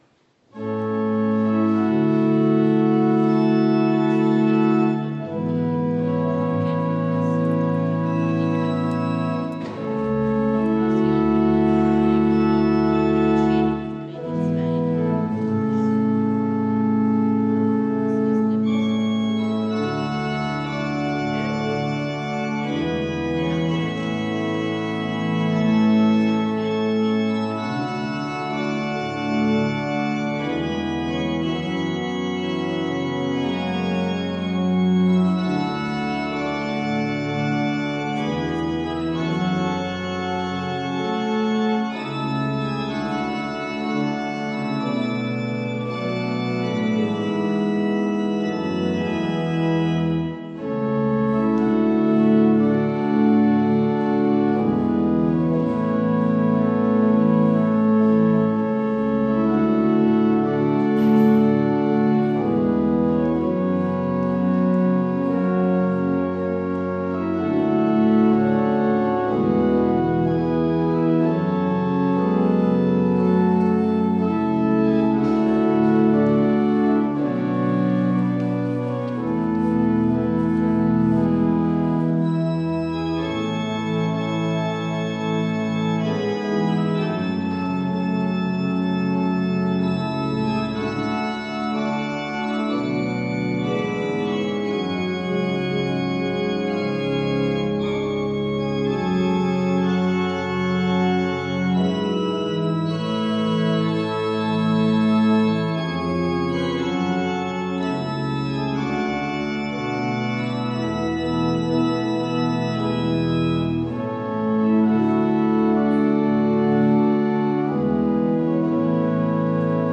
Gottesdienst am 17.10.2021